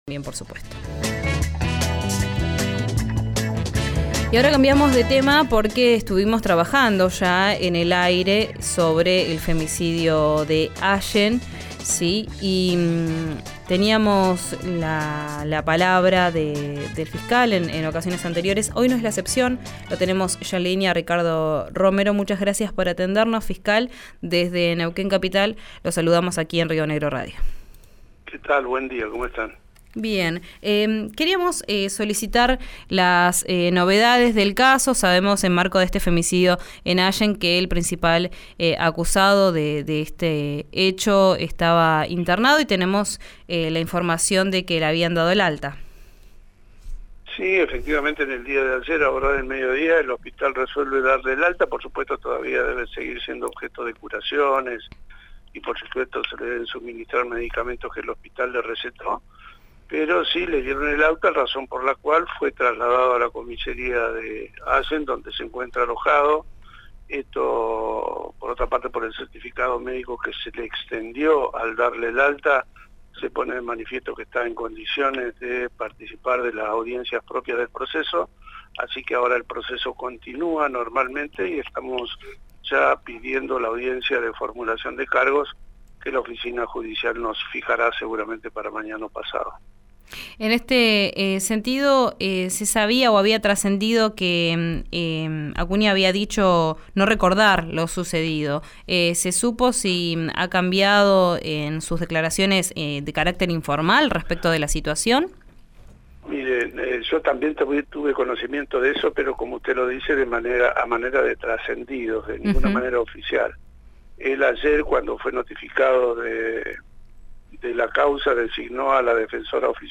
Escuchá al fiscal Ricardo Romero en “Vos al aire”, por RÍO NEGRO RADIO